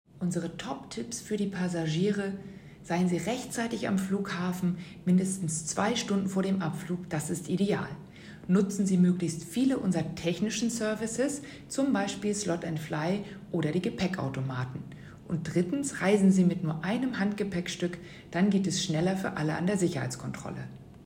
O-Töne: